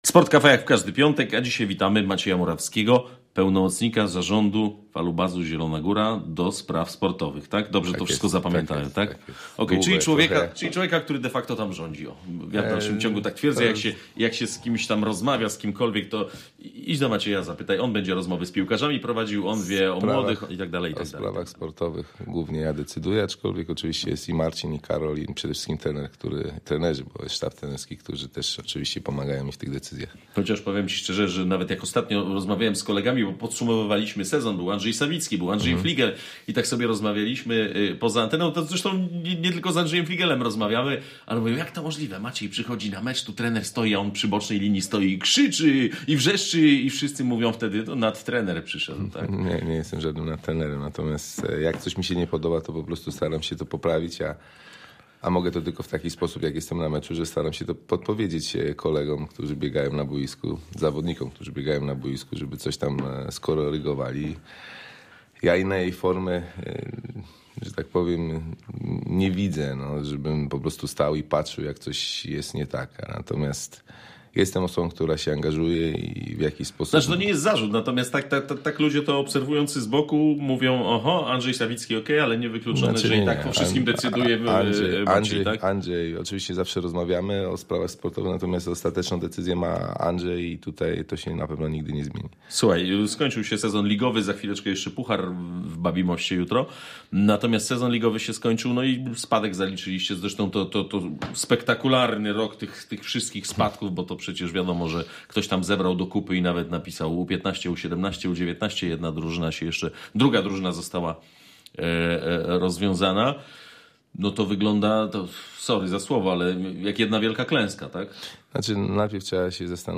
W Sport cafe gościmy dziś Macieja Murawskiego – pełnomocnika zarządu piłkarskiego Falubazu ds. sportowych. Podsumowujemy trudną wiosnę III-ligową i spadek I drużyny oraz innych – młodzieżowych – ekip klubu.